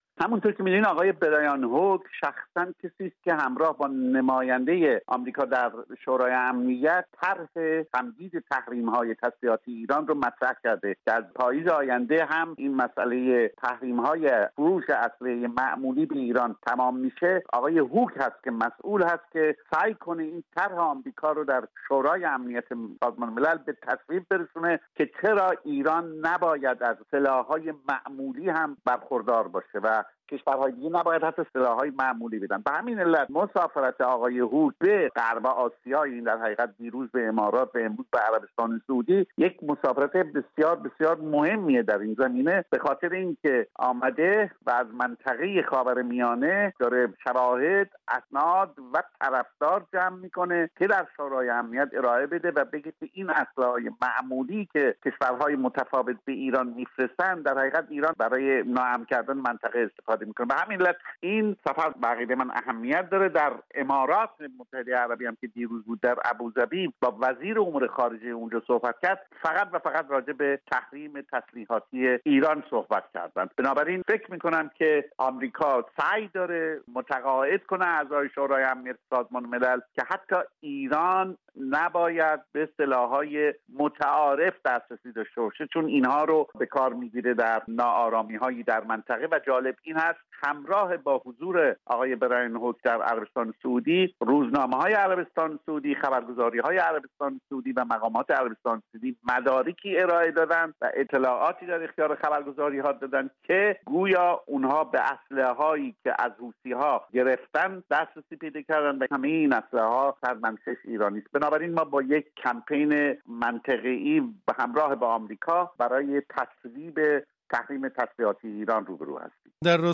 نماینده ویژه وزارت خارجه آمریکا در امور ایران، برایان هوک، در سفر به کشورهای حوزه خلیج فارس اظهار داشت شورای امنیت سازمان ملل در مورد تحریم تسلیحاتی جمهوری اسلامی باید نگرانی کشورهای منطقه را مدنظر قرار دهد. گفت‌وگویی در این زمینه